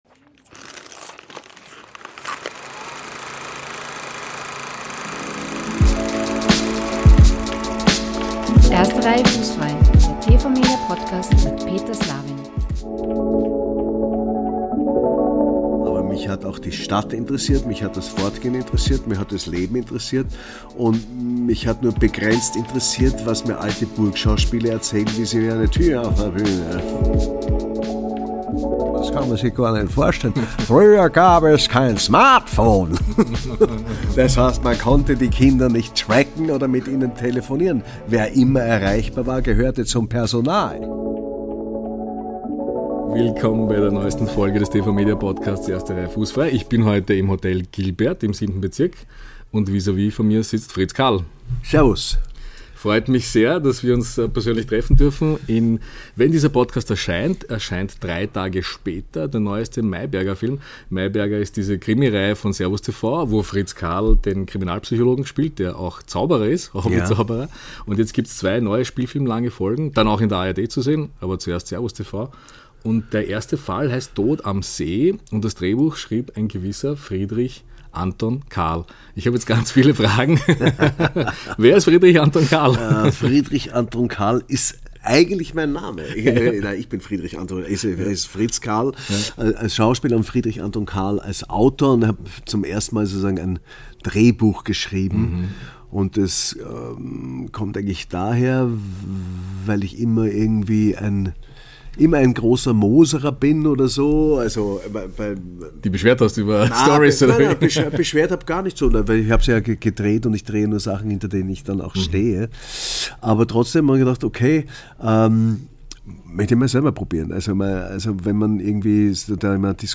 – Ein Gespräch über die besten Filme aller Zeiten.